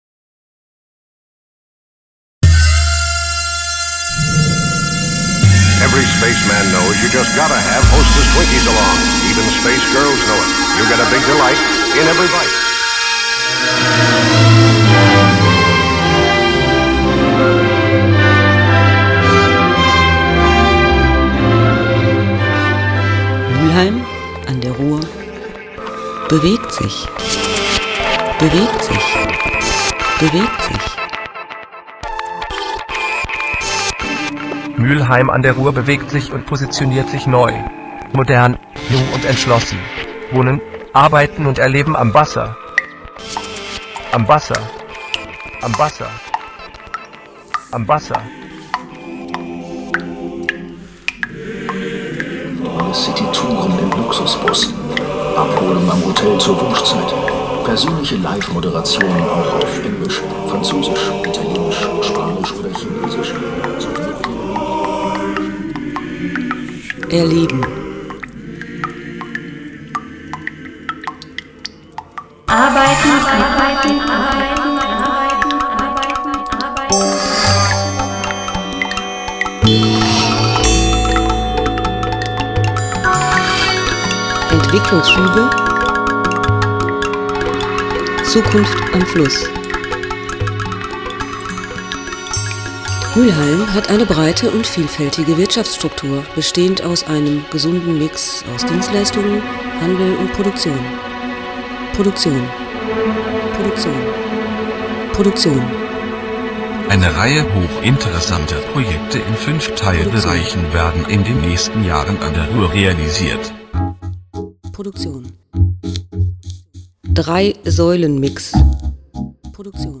Eine Klangcollage (Auftragskomposition) zum Festakt 200 Jahre